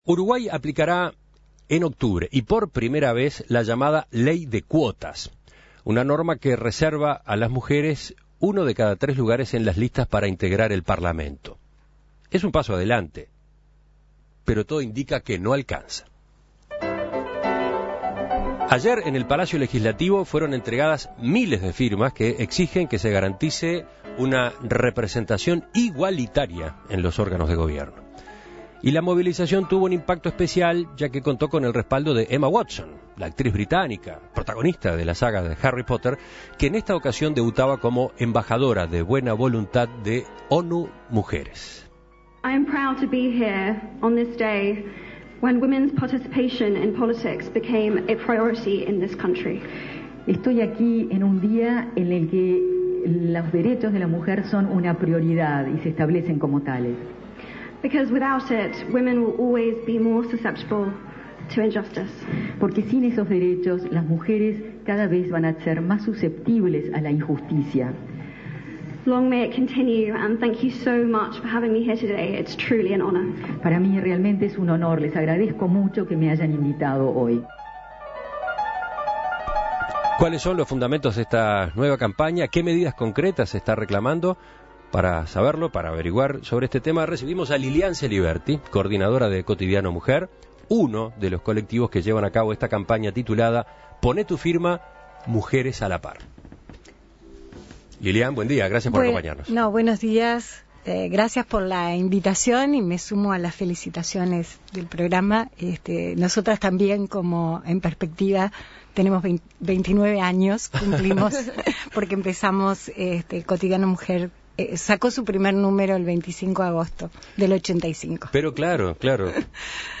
En la jornada de este miércoles el Palacio Legislativo se vio colmado de gente por la presentación de firmas que exigen la representación igualitaria en los órganos de gobierno. Este acto estuvo acompañado por la presencia de la actriz Emma Watson, embajadora de la buena voluntad de ONU Mujeres. Por este motivo, En Perspectiva entrevistó